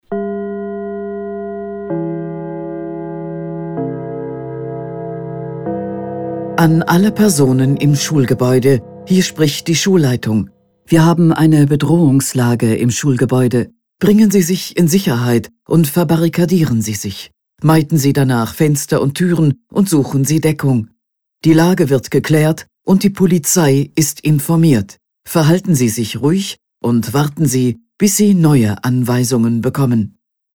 🟢 Hörbeispiel III. – Bedrohungslage in Schule – Amok-Alarmierungs-Ansage:
Schule-neu.mp3